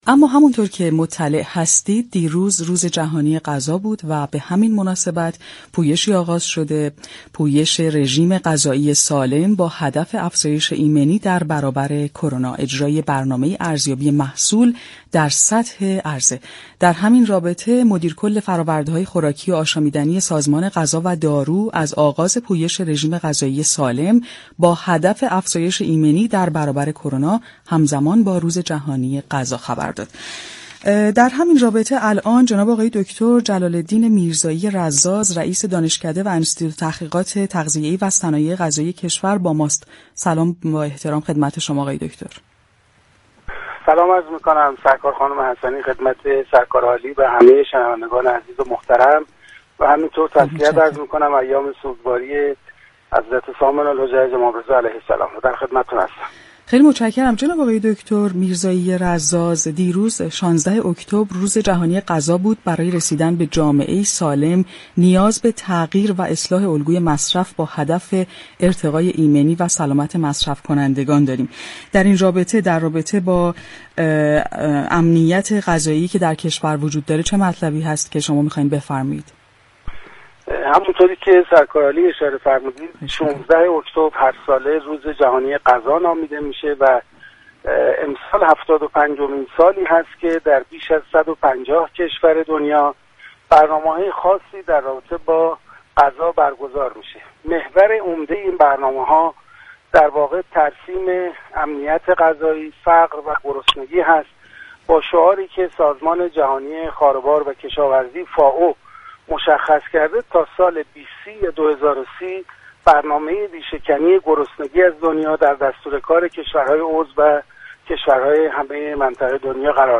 در گفت‌وگو با تهران كلینیك رادیو تهران